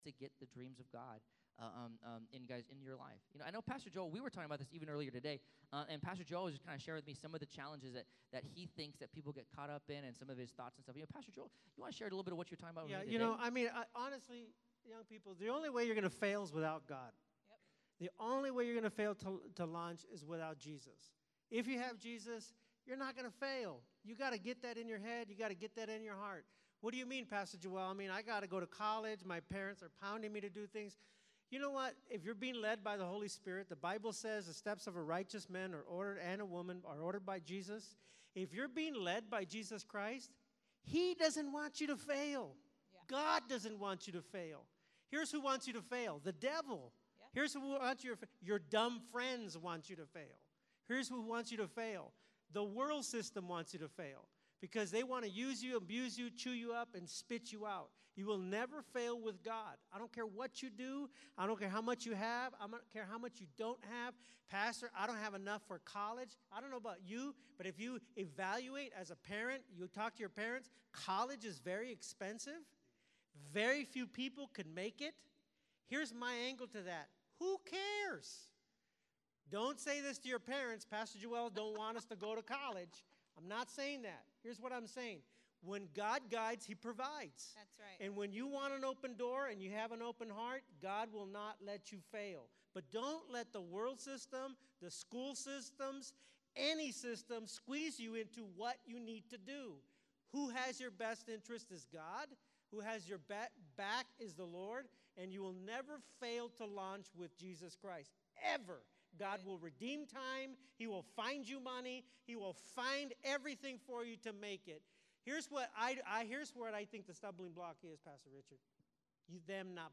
Failure To Launch Panel